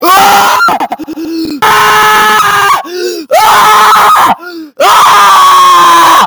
Memes
Loud Scream Funny